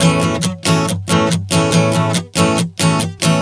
Strum 140 A 04.wav